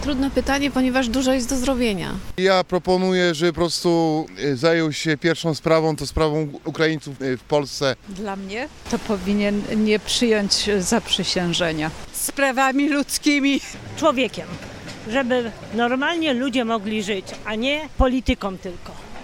Porozmawialiśmy z mieszkańcami Stargardu, by dowiedzieć się, czym według nich nowy prezydent powinien zająć się w pierwszej kolejności.